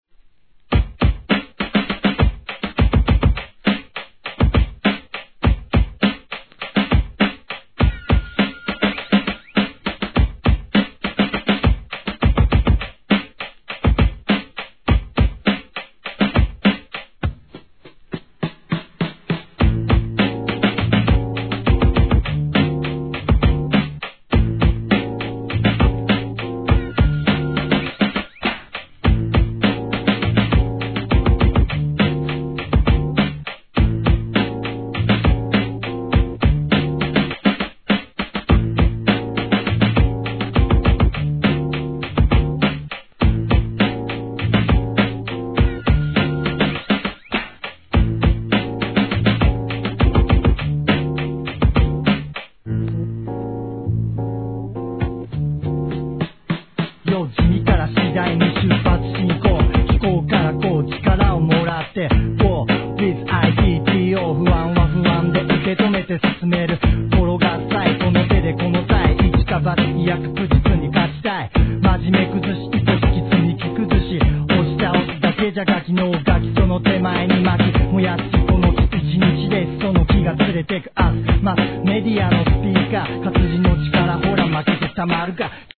1. JAPANESE HIP HOP/R&B